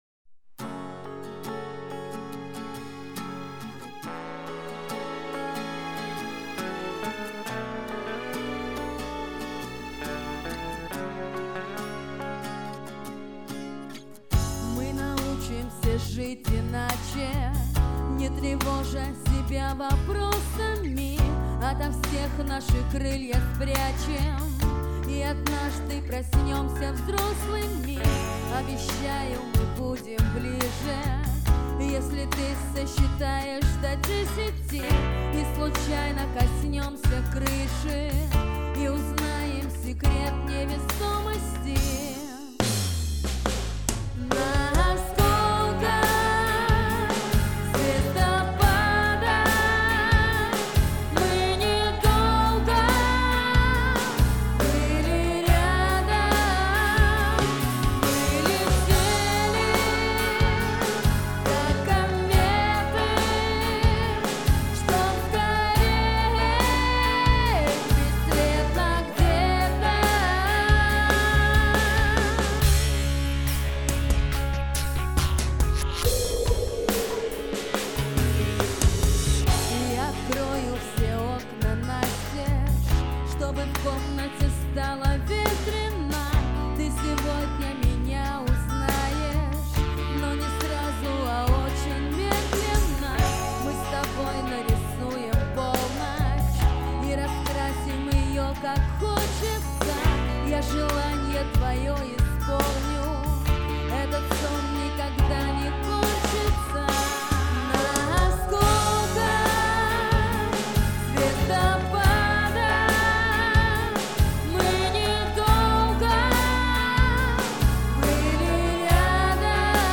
мне дался припевчик, именно по-этому с напором))